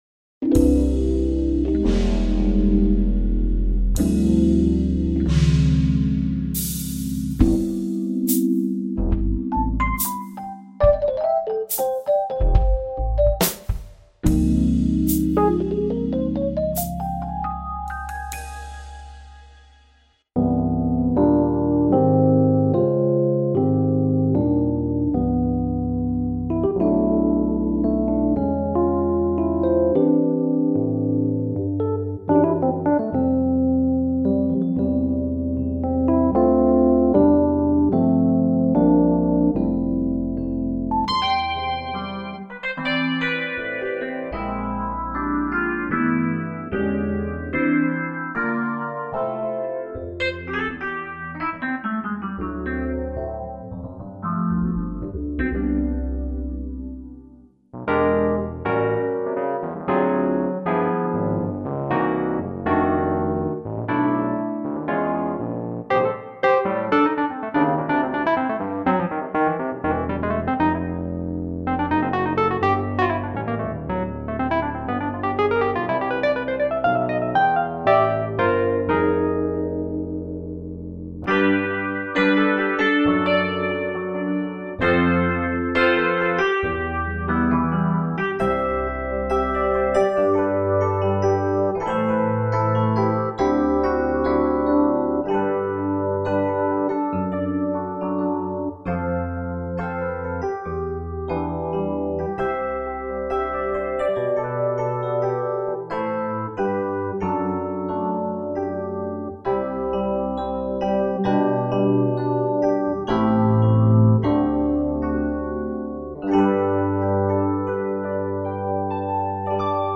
エレクトリックピアノ